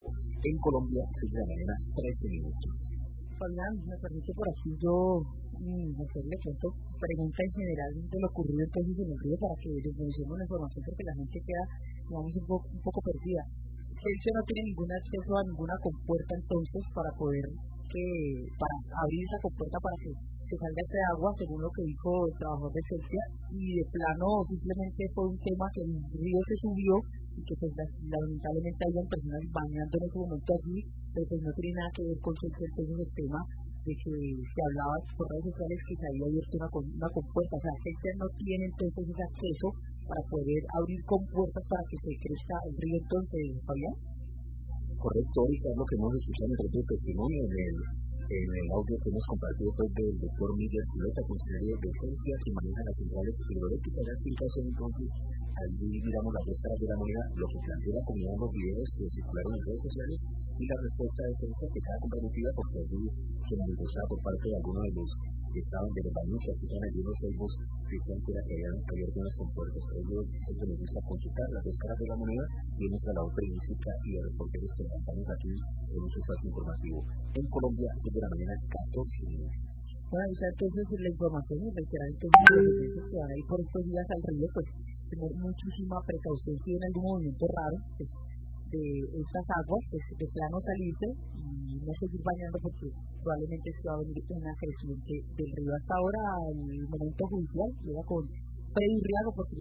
Periodista reitera explicación de Celsia sobre creciente en Los Ceibos
Radio